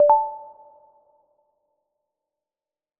harmony2 - Dream Sounds: Harmony 2 sound theme for KDE Plasma
message-new-instant.ogg